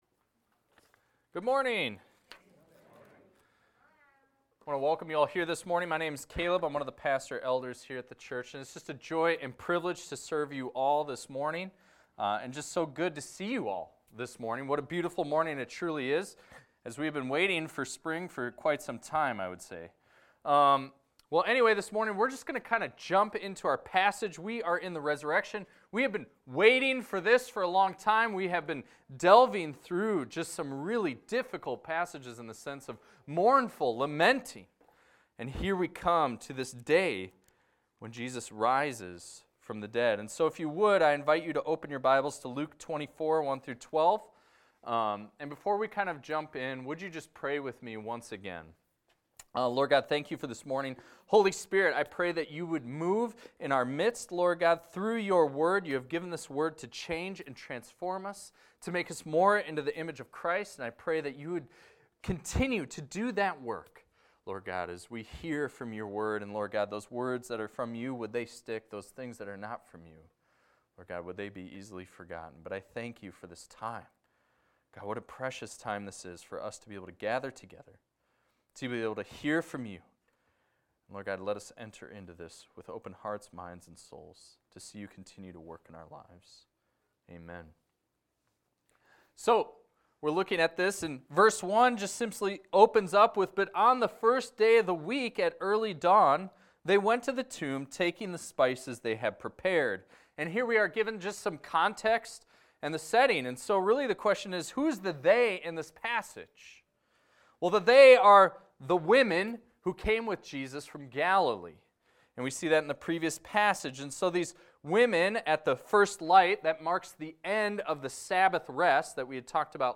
This is a recording of a sermon titled, "The Son Has Risen!."